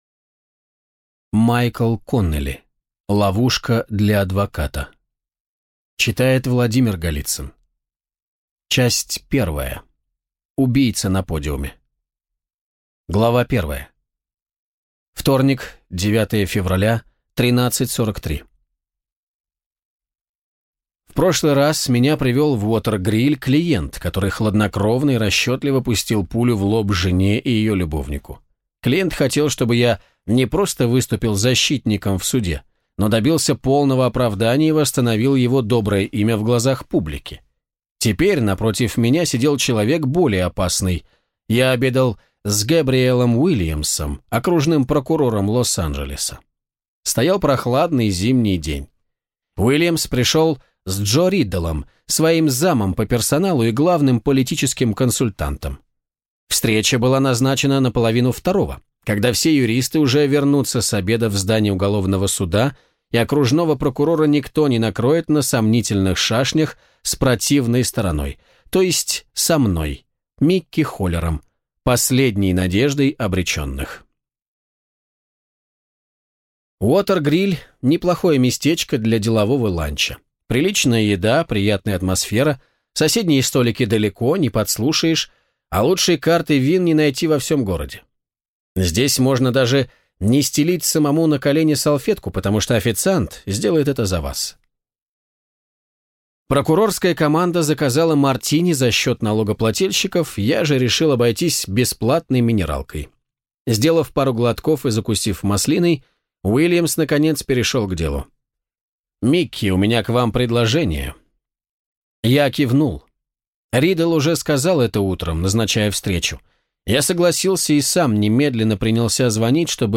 Аудиокнига Ловушка для адвоката | Библиотека аудиокниг